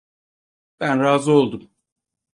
Pronunciado como (IPA)
/ɾaːˈzɯ/